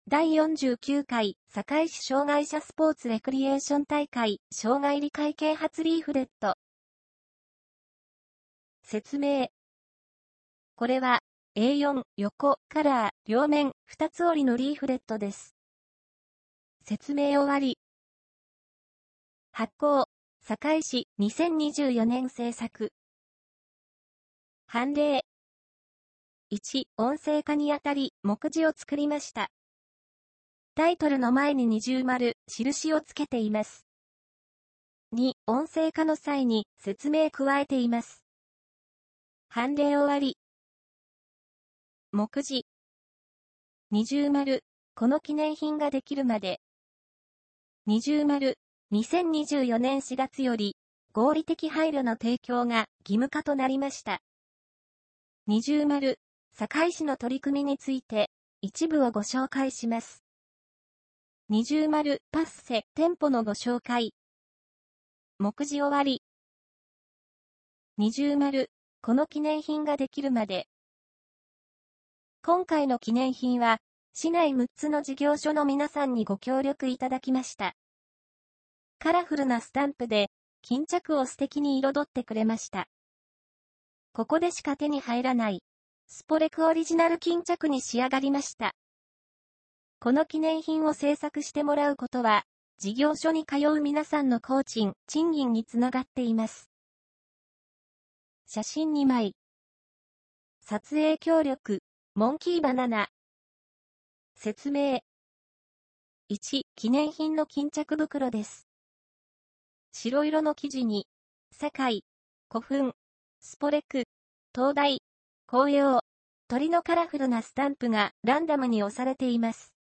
第49回堺市障害者スポーツ・レクリエーション大会障害理解啓発リーフレット（合成音声版）